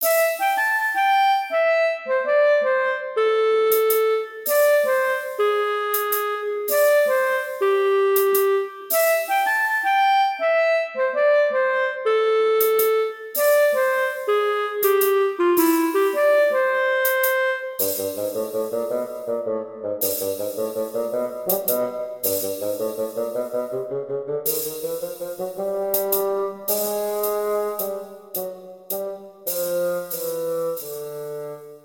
少し寂しい気持ちを吐露するクラリネットとそれを受け取り励ますファゴット。
ショートループ